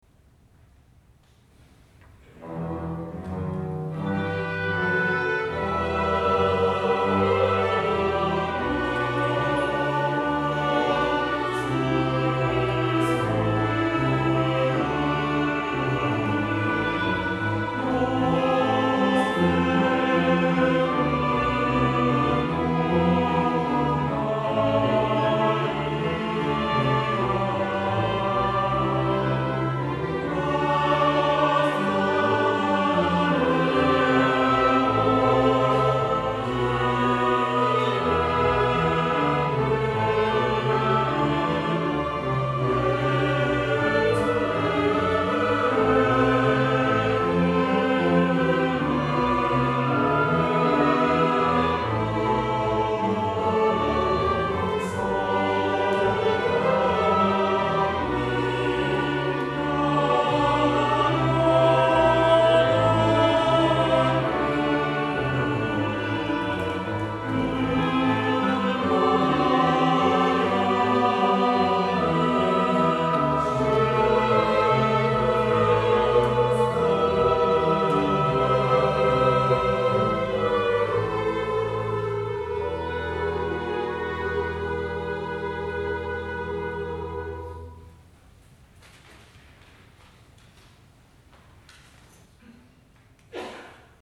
First performance Bach Chor, ToKyo - ARS on 15 Jun 2024 at Misakicho Church
Midi Instrumentall ensemble (Ob-Ob-Eh-Fg-Str)